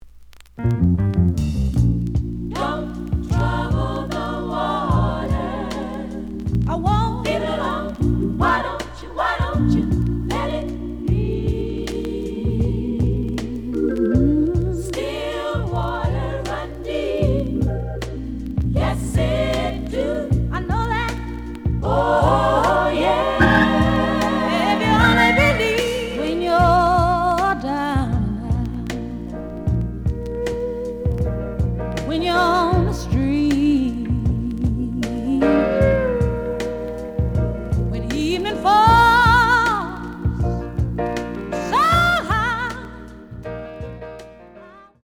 The audio sample is recorded from the actual item.
●Genre: Soul, 60's Soul
Slight edge warp. But doesn't affect playing. Plays good.)